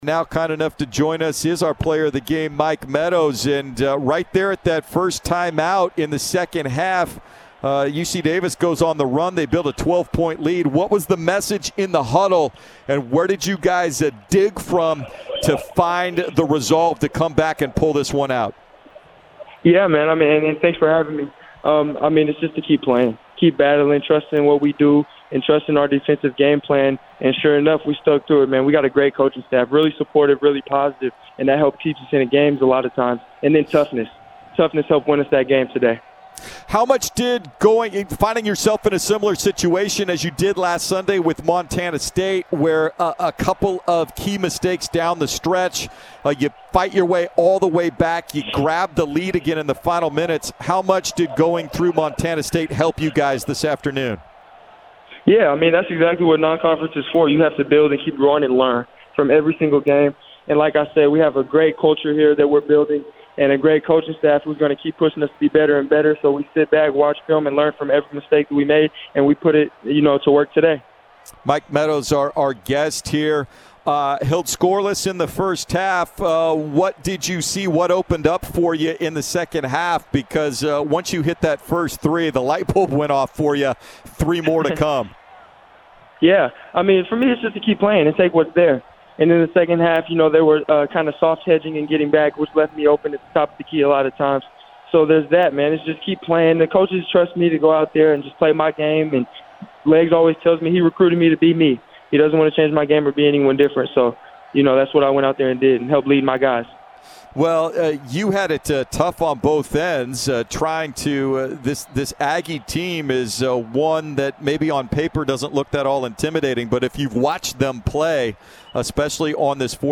UC Davis Radio Interview